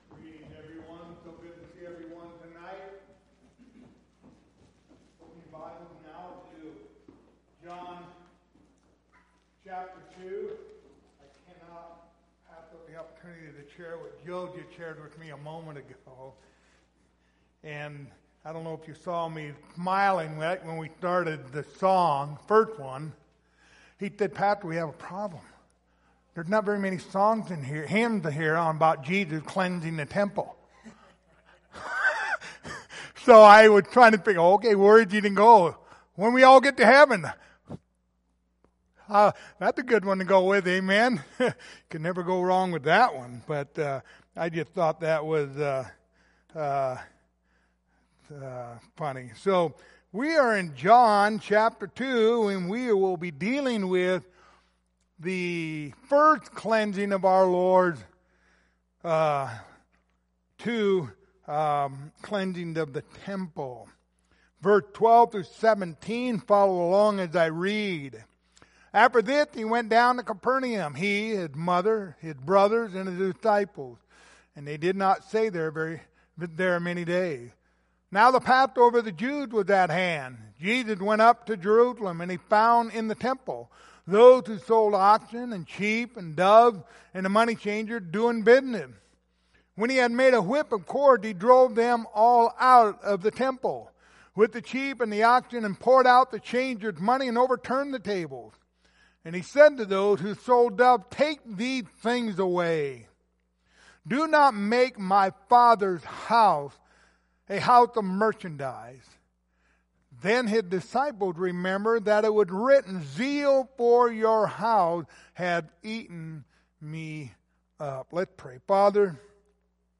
Passage: John 2:12-17 Service Type: Wednesday Evening